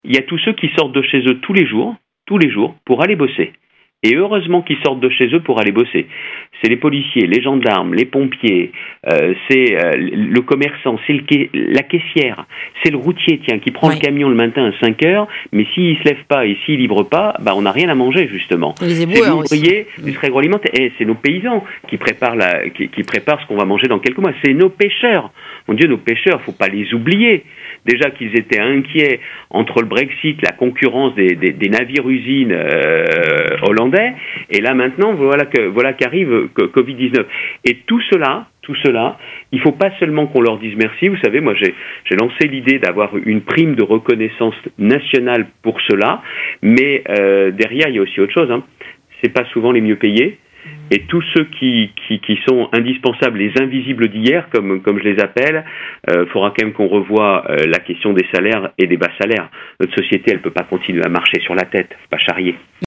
Invité de RADIO 6 hier, le président des Hauts-de-France est revenu longuement sur les "les invisibles d'hier" qui permettent au pays de tourner en plein crise sanitaire. Il faut, selon Xavier Bertrand, revoir la question des bas salaires et la situation des travailleurs en Ehpad.